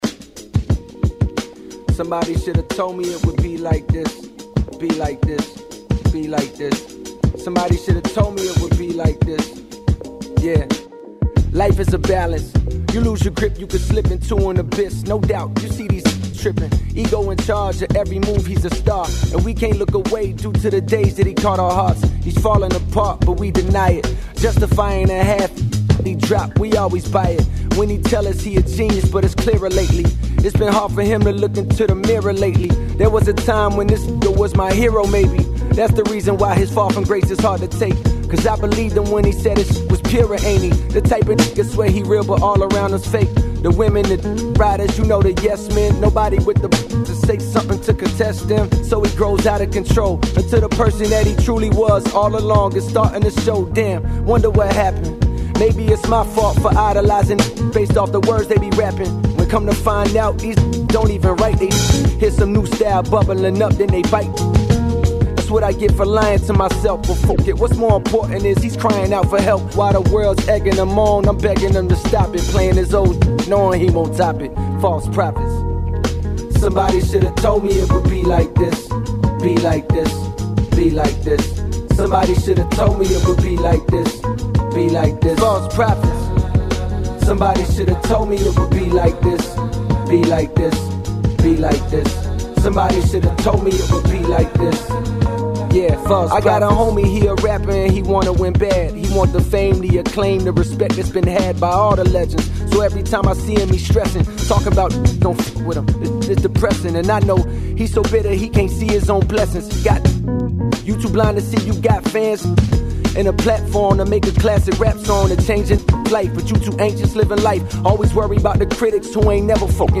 On this show, you’ll hear recent news, personal experiences, and a diverse selection of music. Youth Radio Raw is a weekly radio show produced by Bay Area high schoolers, ages 14-18.